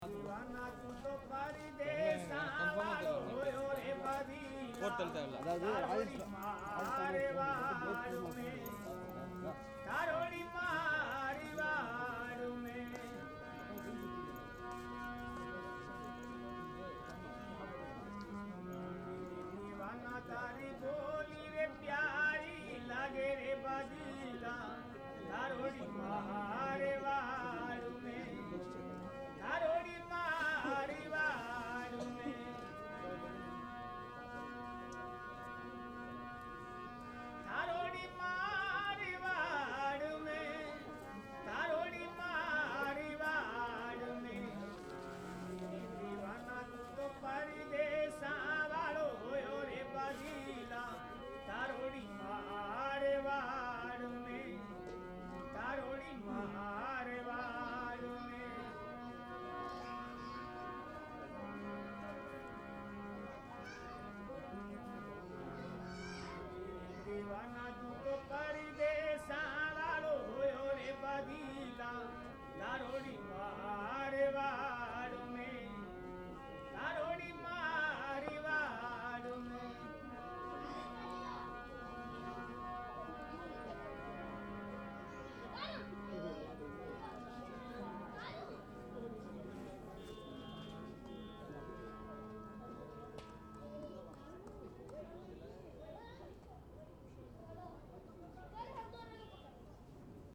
musicien_jodh2.mp3